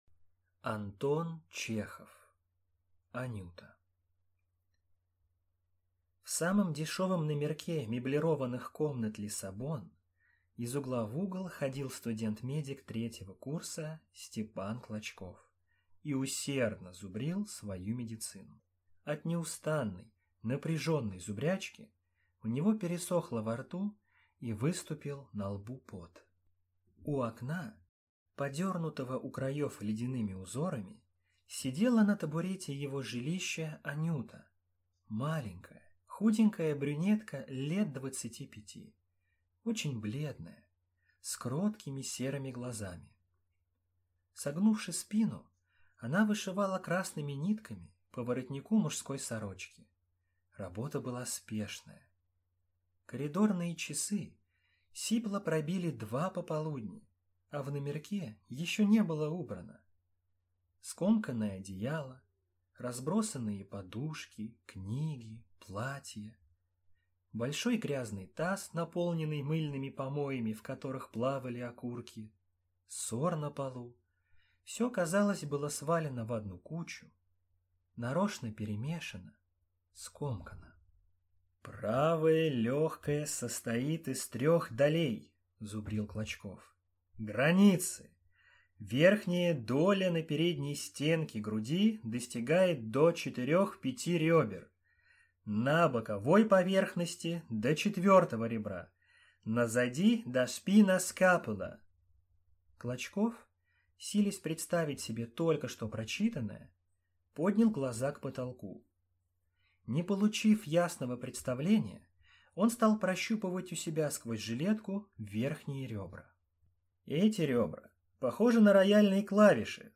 Аудиокнига Анюта | Библиотека аудиокниг